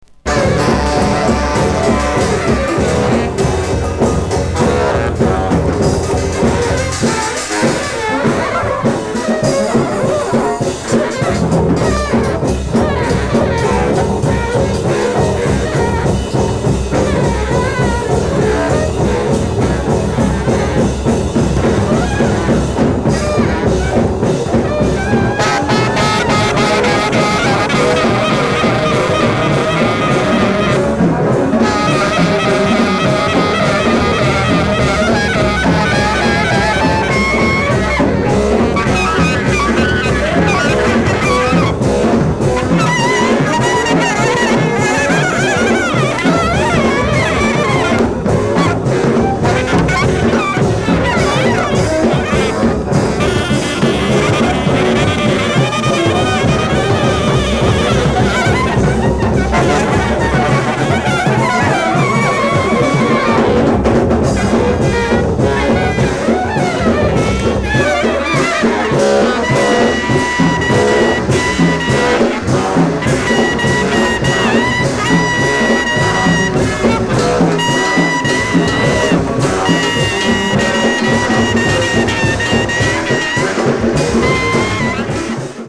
discription:Stereo